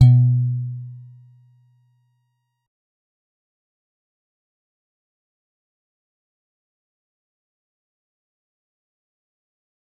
G_Musicbox-B2-mf.wav